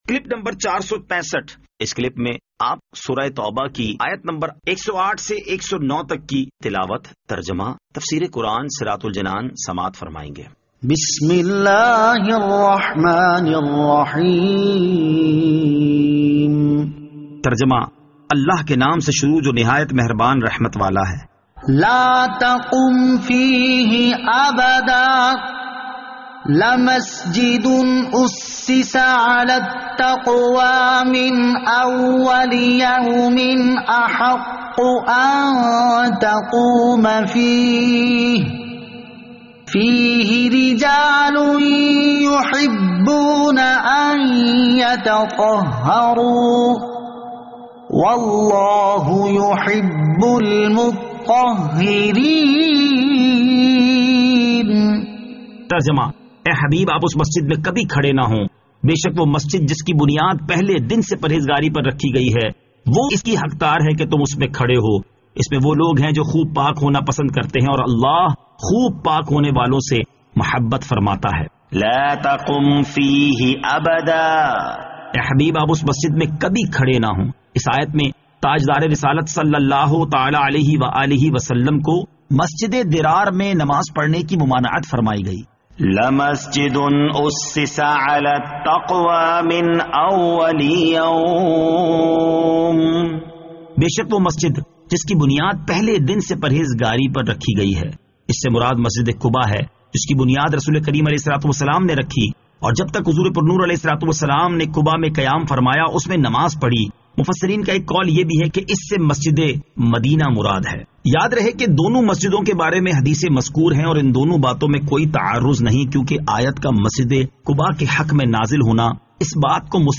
Surah At-Tawbah Ayat 108 To 109 Tilawat , Tarjama , Tafseer